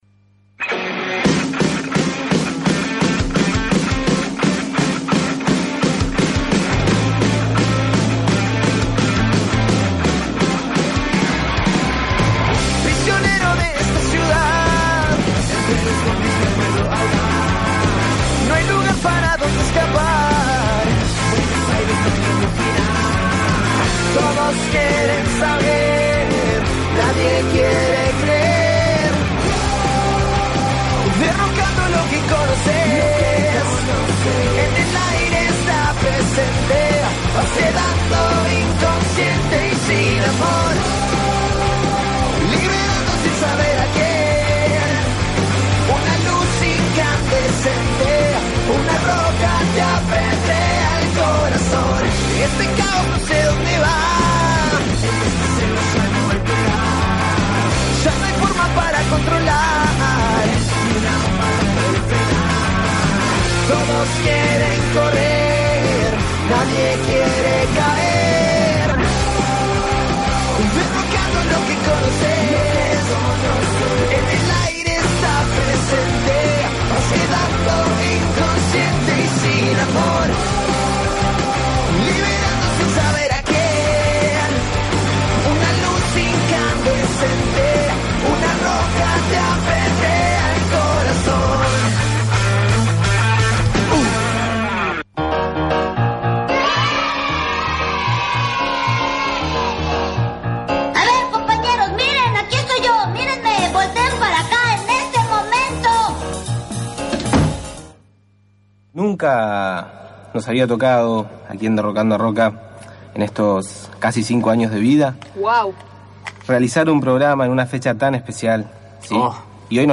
_ Nos tocó programa un 9 de julio, y encima del Bicentenario. Para estar a tono arrancamos con algunas versiones particulares del himno.